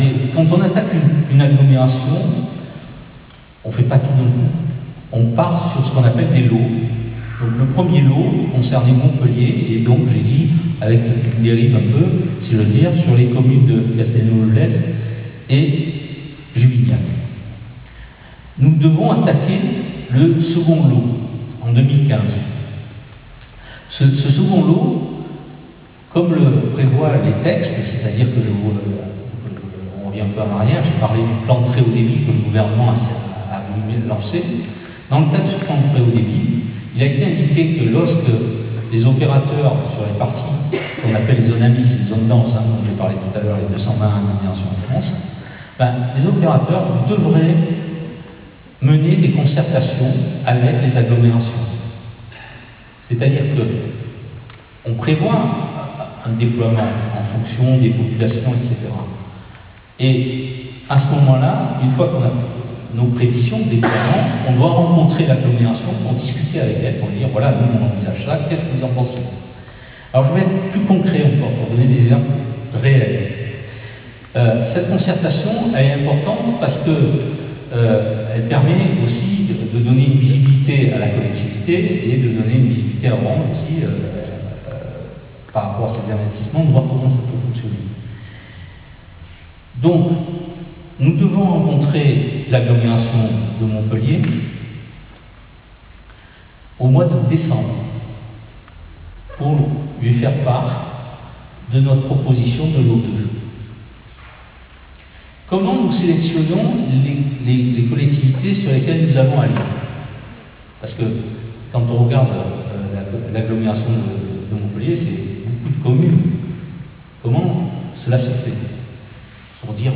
C.R. REUNION PUBLIQUE INTERNET HAUT DEBIT AU CRES : ORANGE demande aux créssois d'attendre des jours meilleurs .... des années plus exactement !